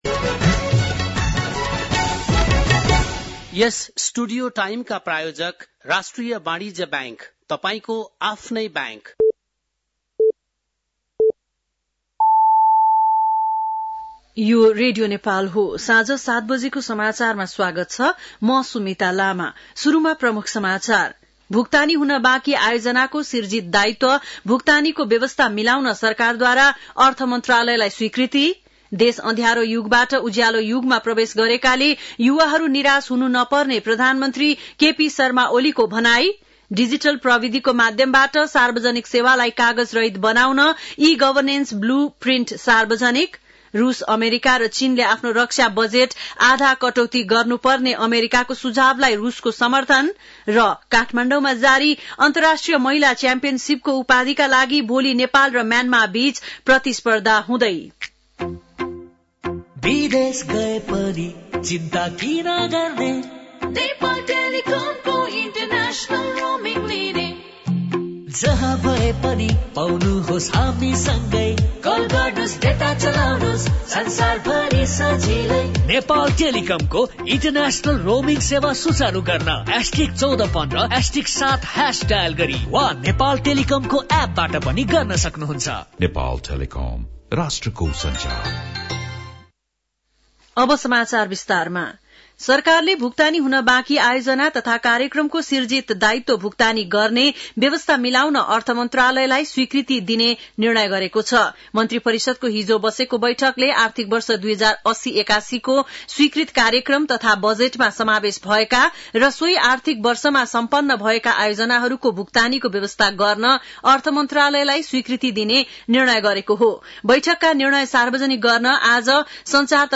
बेलुकी ७ बजेको नेपाली समाचार : १४ फागुन , २०८१